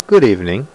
Good Evening Sound Effect
good-evening.mp3